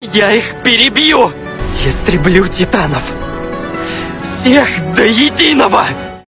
голосовые